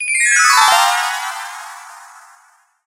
何かキラキラした感じの効果音。クリスタルな感じの効果音。高音のキラキラした音。